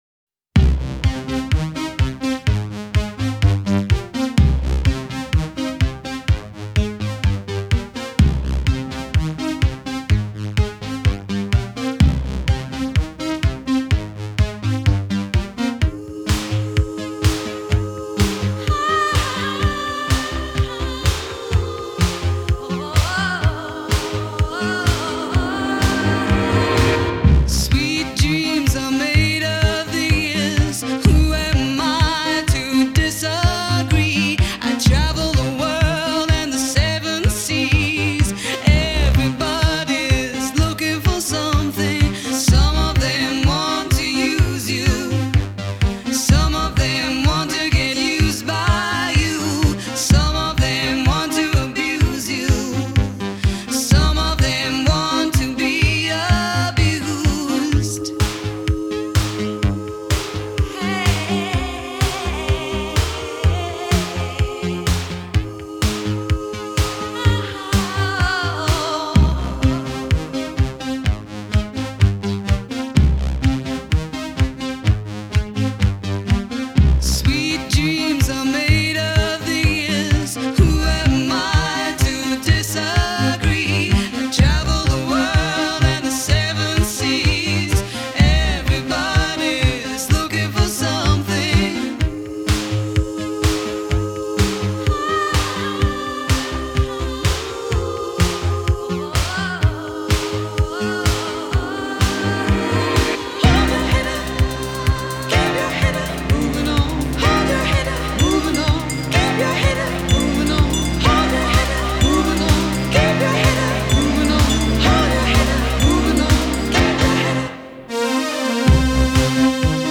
синти-поп-дуэт